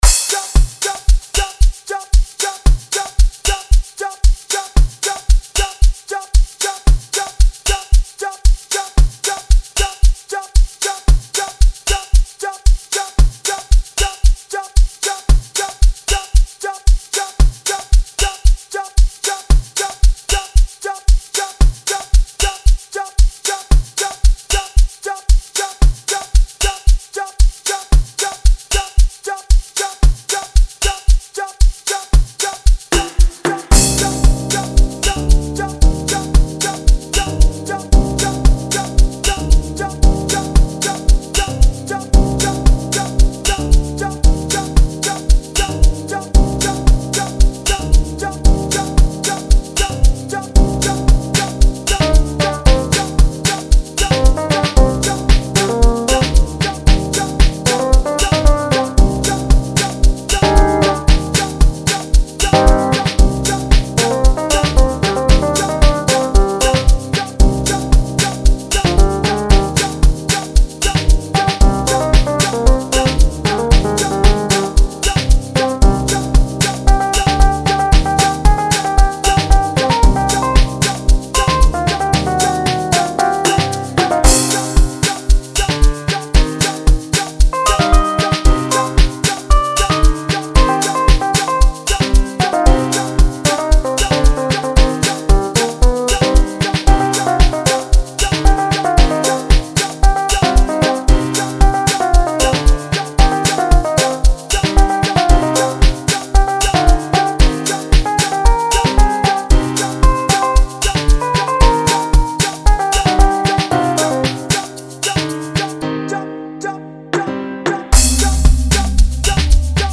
pianotic melodies
Amapiano